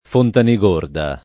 [ fontani g1 rda ]